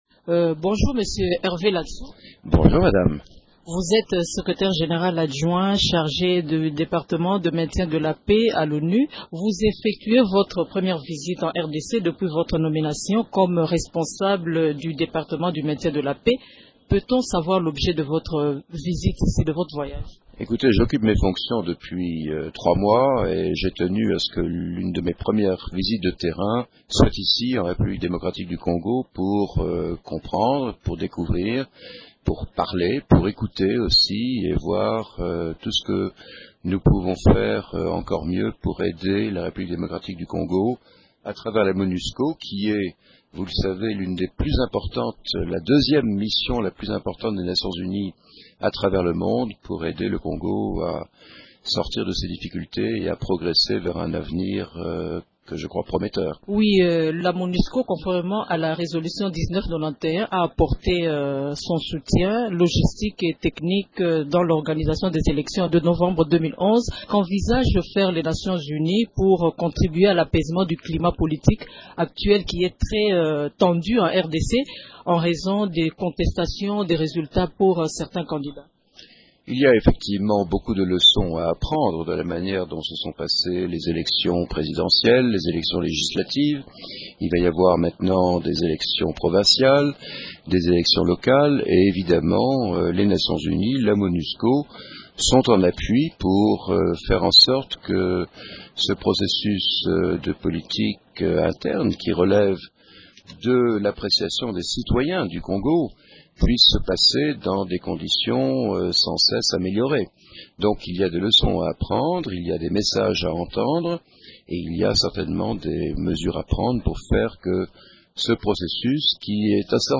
Hervé Ladsous a déclaré dans cet entretien